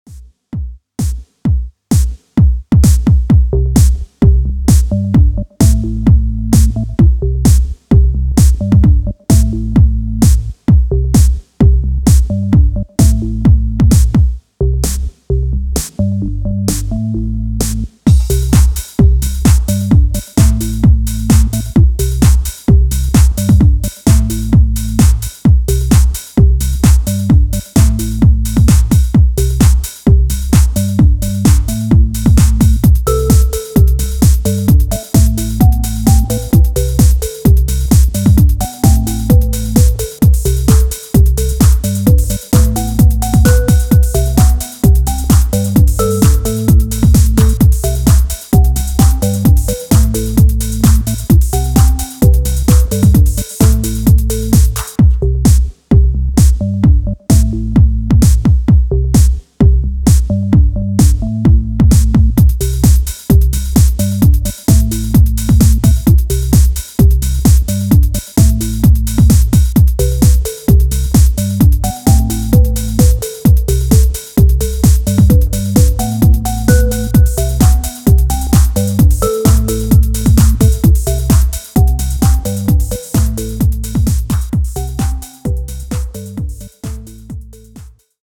Catchy basslines and irresistible grooves